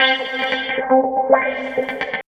136 GTR 3 -L.wav